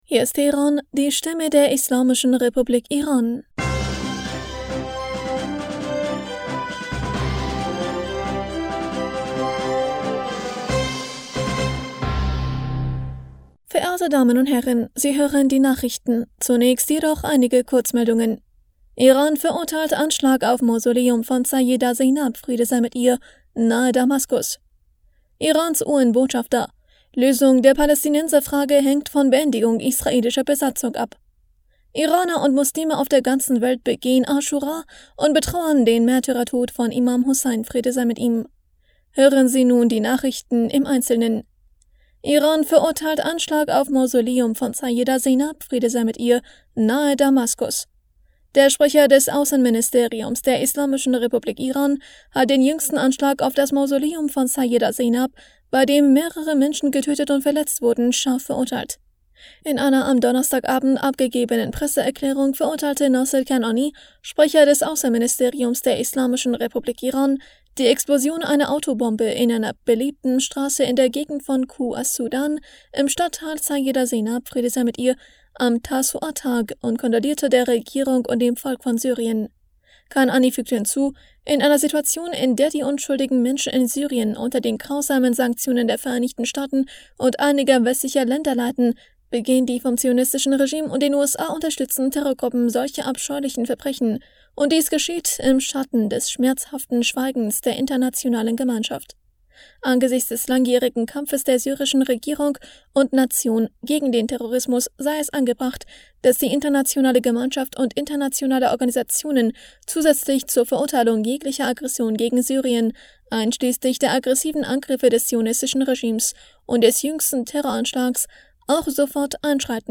Nachrichten vom 28. Juli 2023